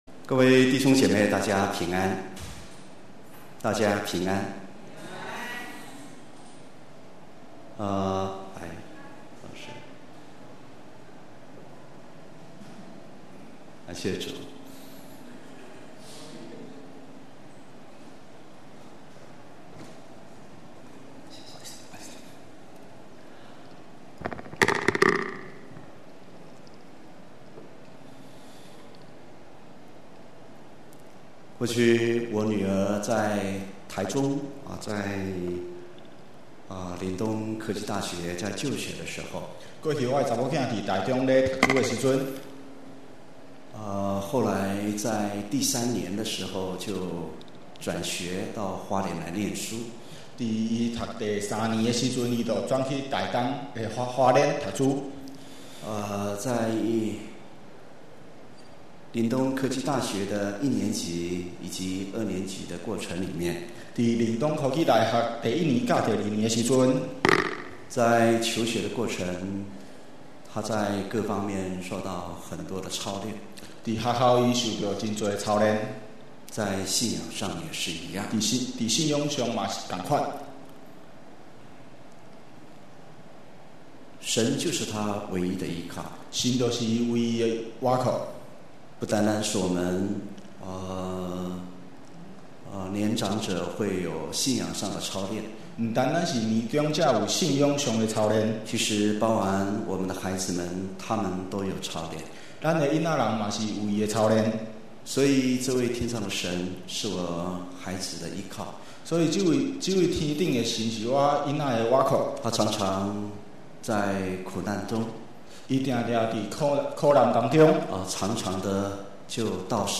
2018年9月份講道錄音已全部上線